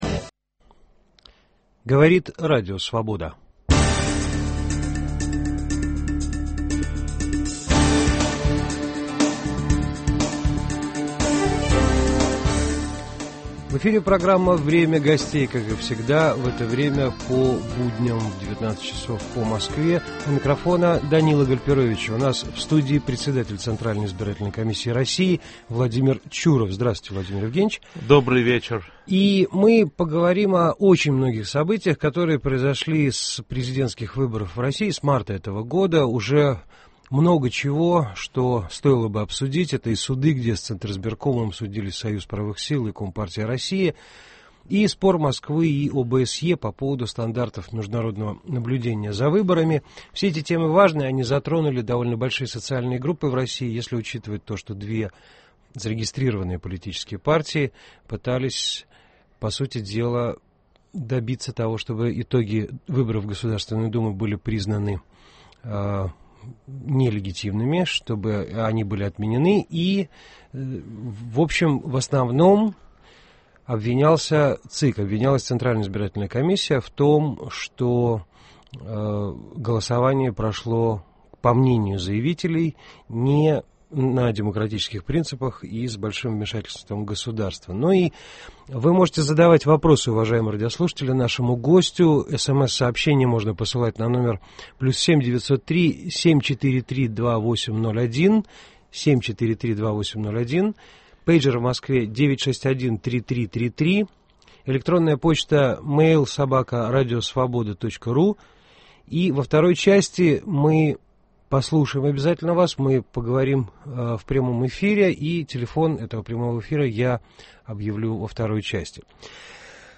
Разговор о разногласиях России и ОБСЕ, изменениях в избирательном законодательстве и обсуждение недавних судебных исков к Центризбиркому. Гость программы - председатель Центральной избирательной комиссии России Владимир Чуров.